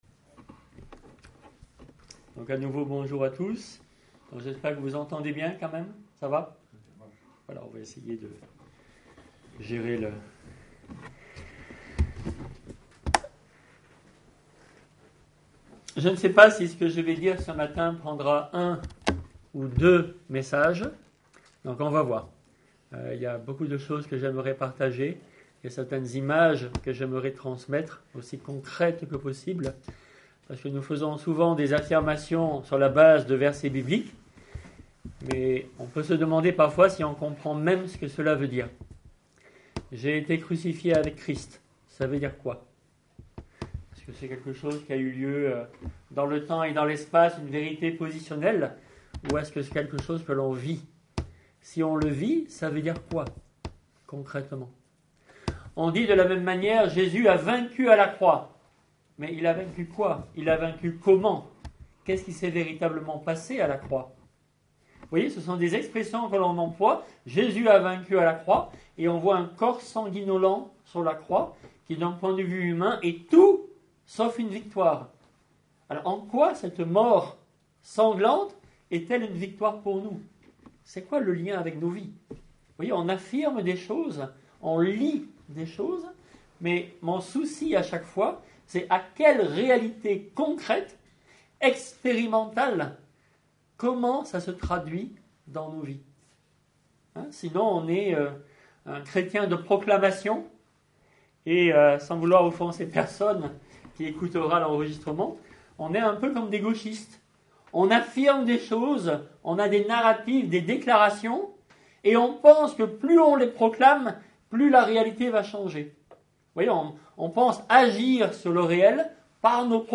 Prédicateurs